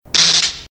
ELECTRICITY CRACKLES.mp3
Power generator has a big discharge, as the crackles are surrounding the microphone.
electricity_crackles_5lg.ogg